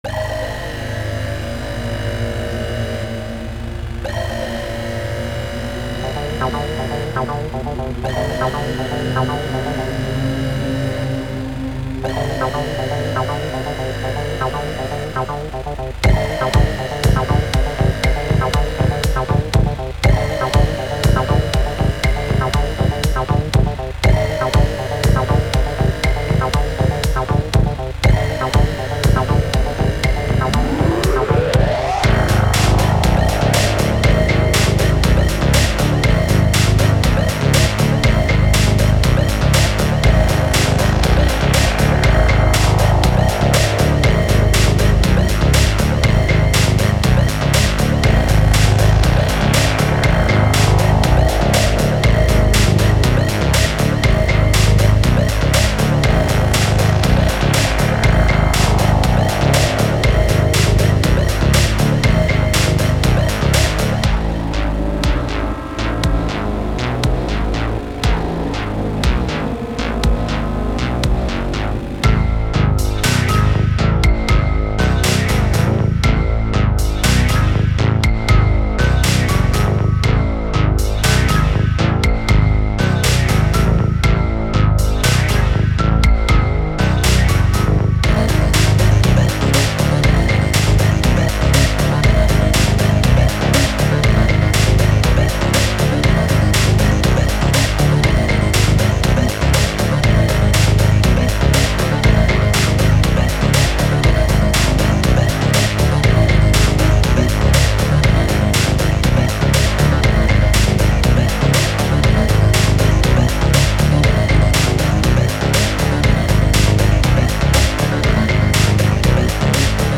Изучаю FL Studio, оцените мои потуги (Ebm/industrial)